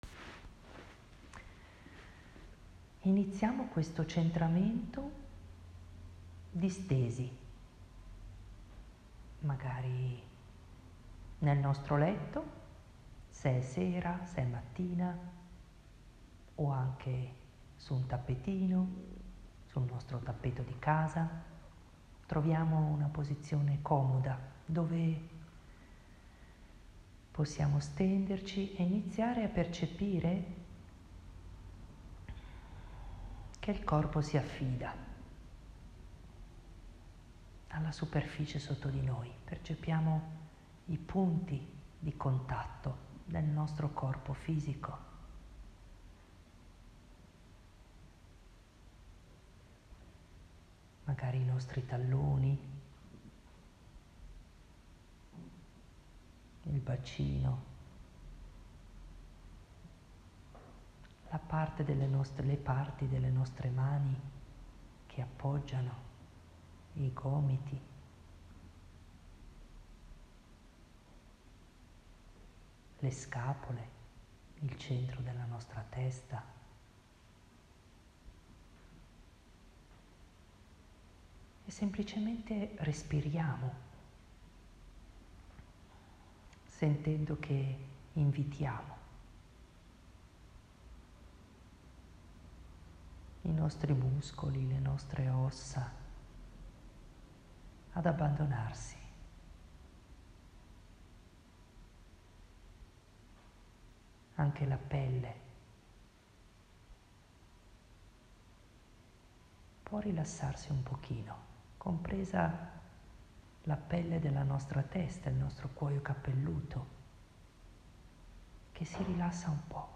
In questa direzione, vi propongo una meditazione per ritornare ai ’semi’ che sono dentro di noi, a quello spazio piccolo e concentrato dove esiste un potenziale che ha bisogno di prendere forma, proprio come nel seme di una pianta.